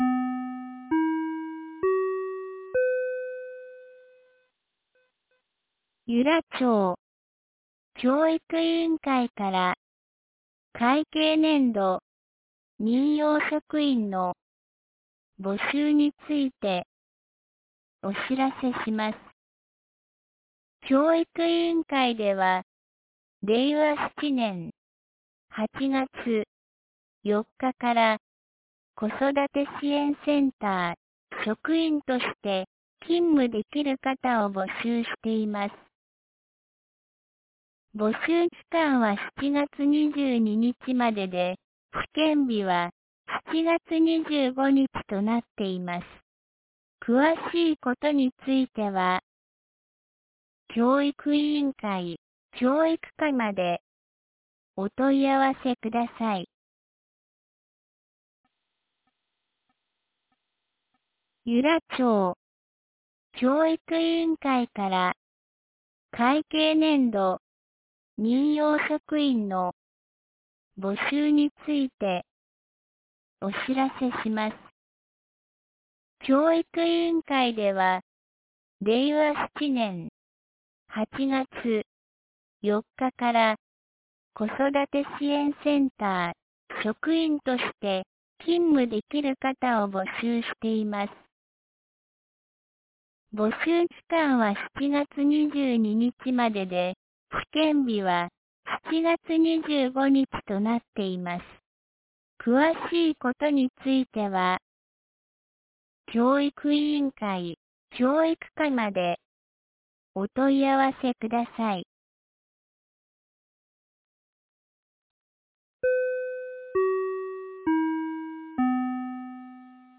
2025年07月11日 17時12分に、由良町から全地区へ放送がありました。